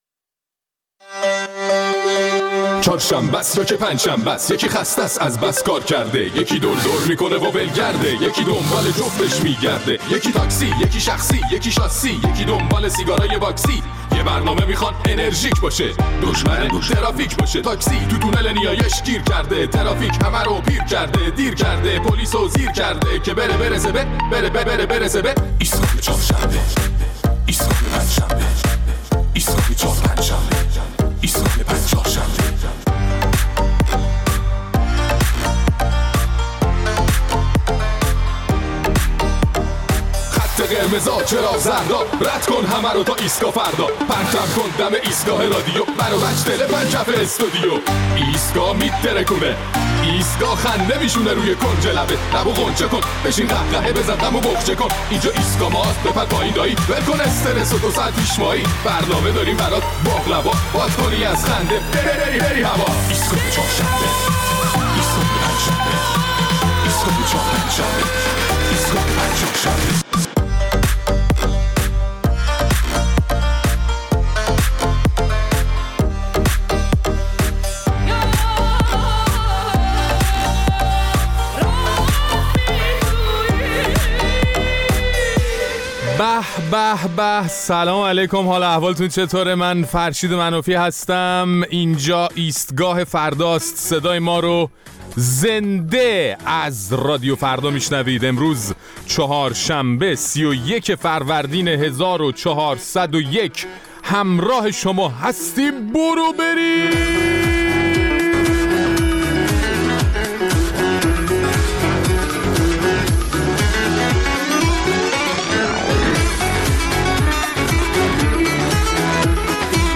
در این برنامه نظرات شنوندگان ایستگاه فردا را در مورد صحبت‌های یک امام جمعه در مورد امتحان الهی بودنِ فقر و گرانی و فساد می‌شنویم.